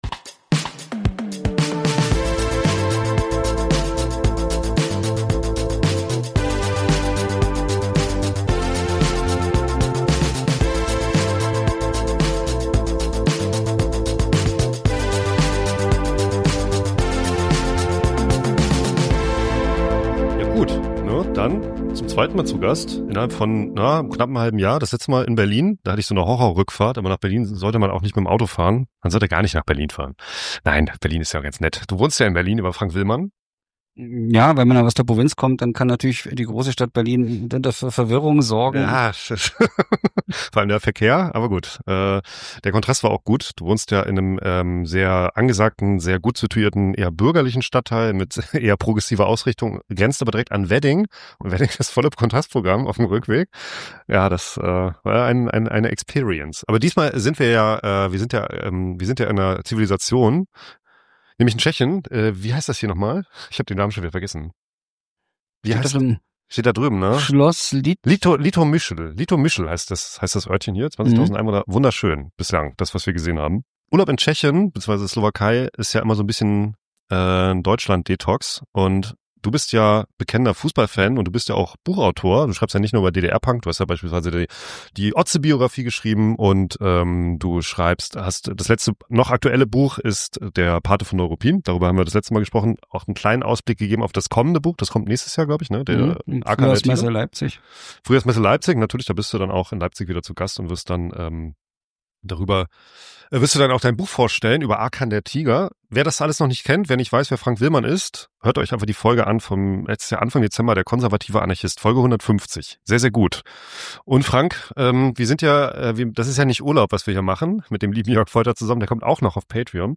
Jedenfalls unterhalte ich mich über den Fußball im wilden Osten